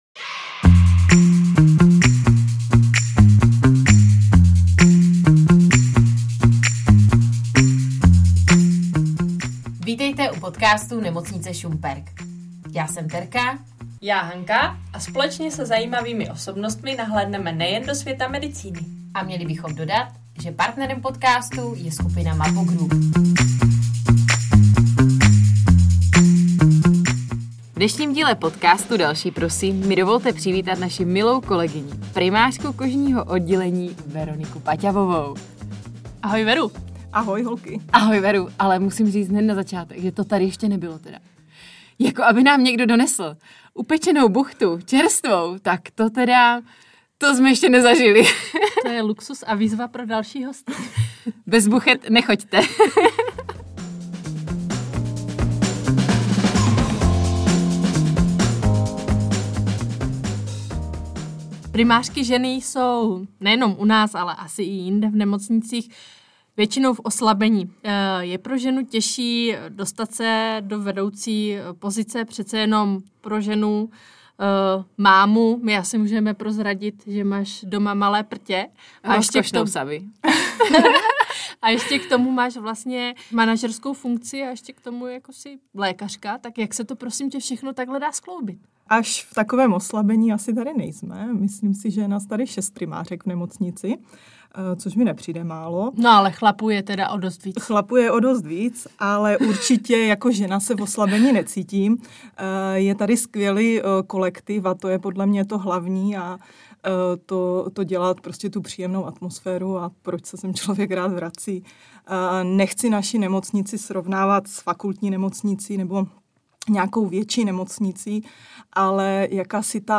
Poslechněte si pohodový rozhovor nejen s primářkou, kolegyní, ale i kamarádkou.